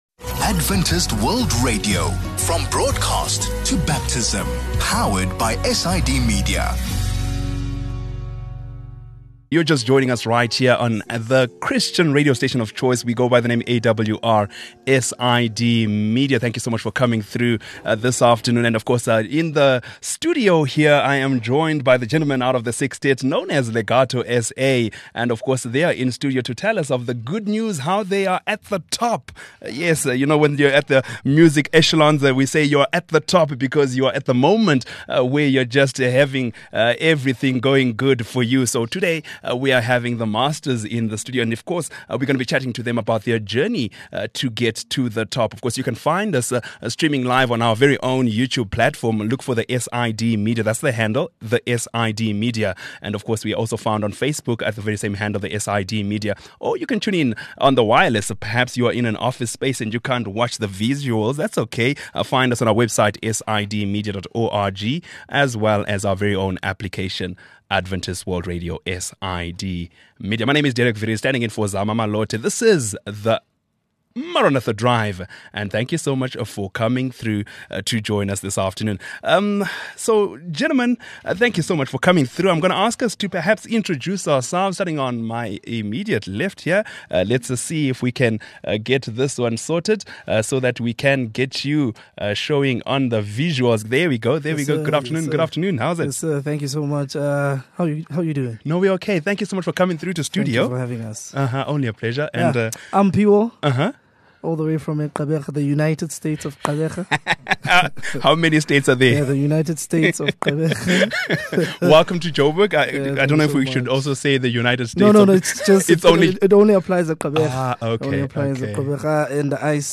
Acappella group Legato SA joins us for a chat following their recent big win at the SABC Crown Gospel Awards.